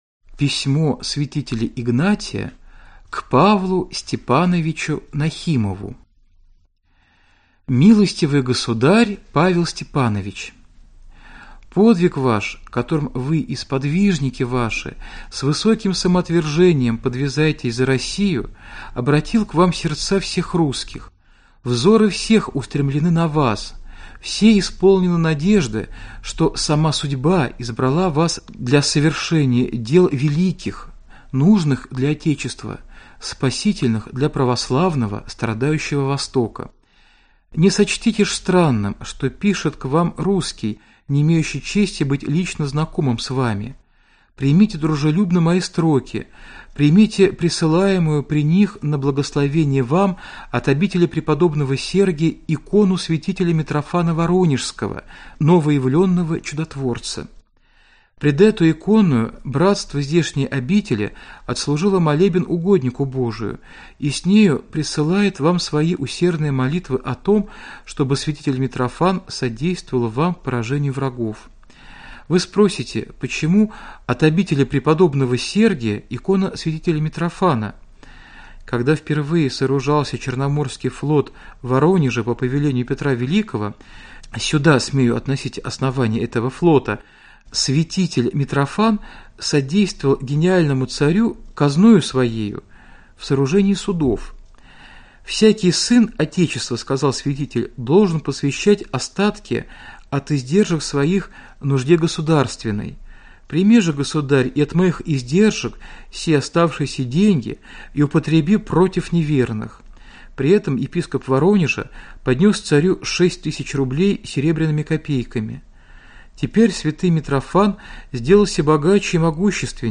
Аудиокнига Письма 2 | Библиотека аудиокниг